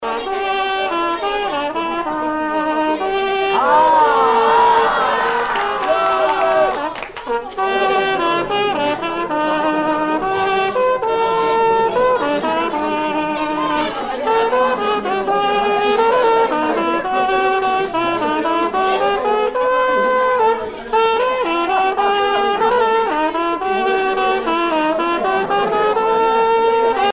Air de marche
circonstance : fiançaille, noce
Pièce musicale éditée